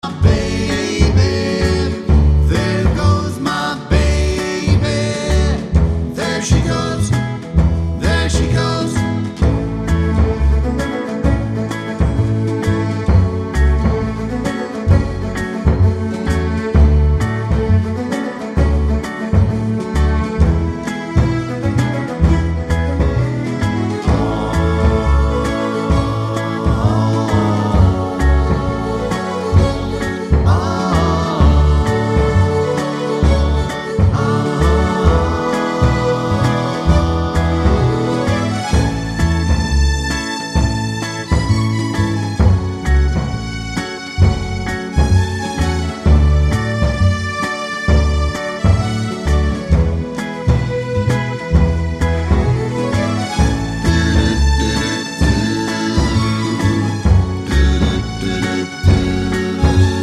no Backing Vocals Soul / Motown 2:22 Buy £1.50